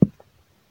beeb kick 10
Tags: 808 drum cat kick kicks hip-hop